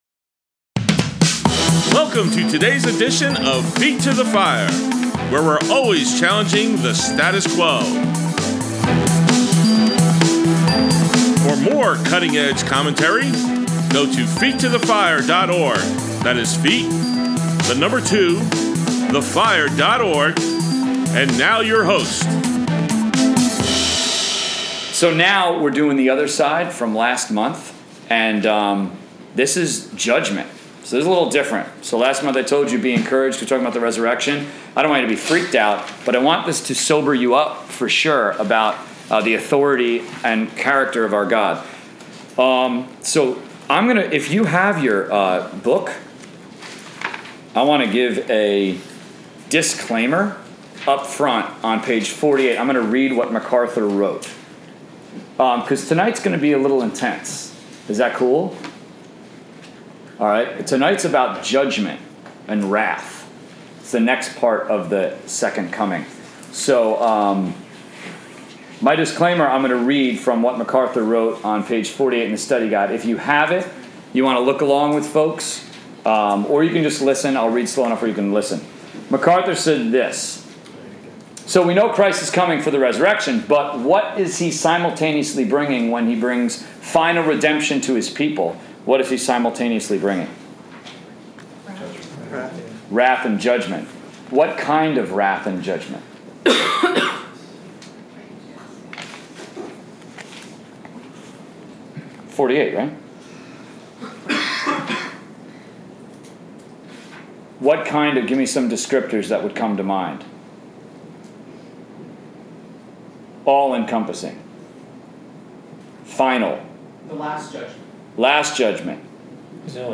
College/Career Bible Study, July 1, 2017: Part 1 of 2